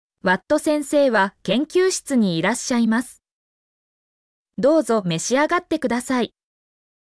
mp3-output-ttsfreedotcom-2_EXZuqrs4.mp3